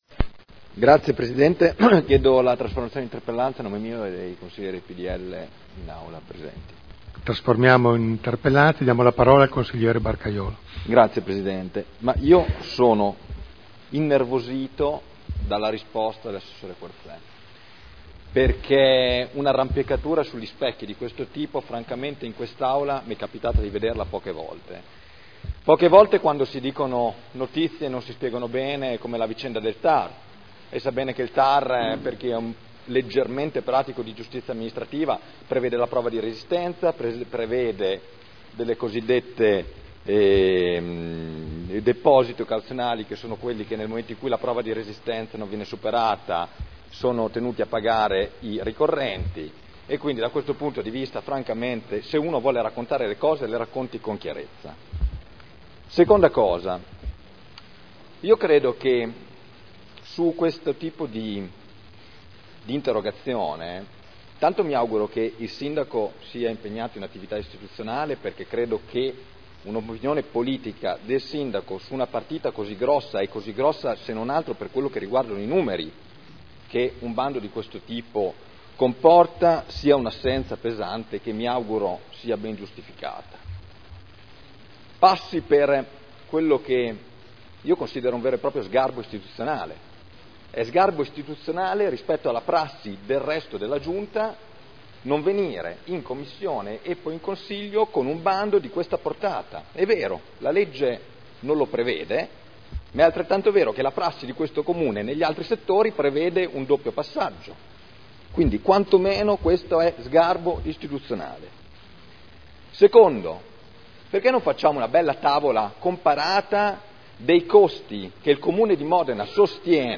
Seduta del 09/01/2012.